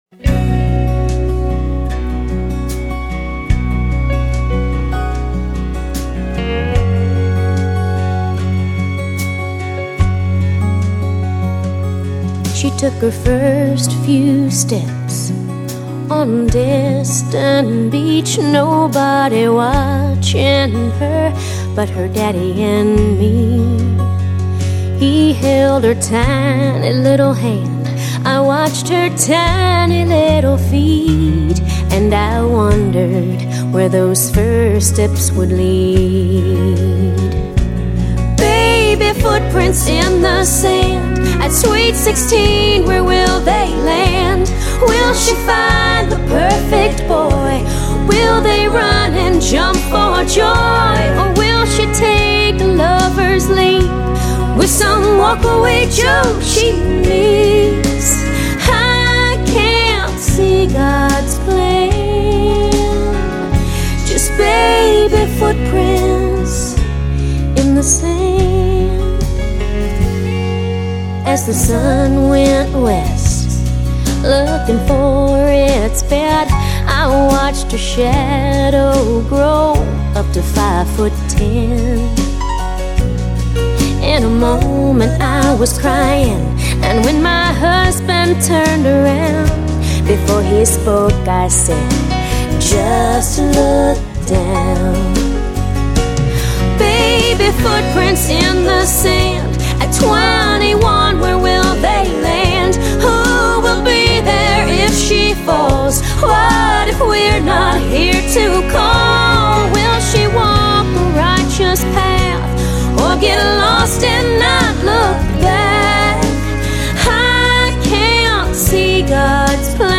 is a sensitive, heartfelt country ballad